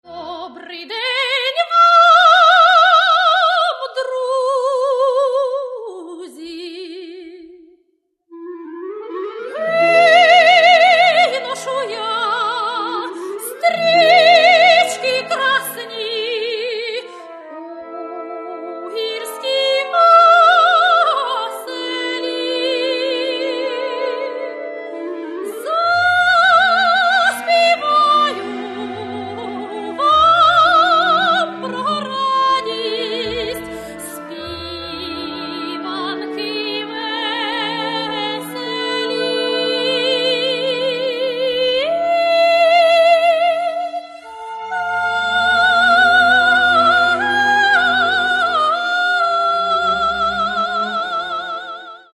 Каталог -> Классическая -> Опера и вокал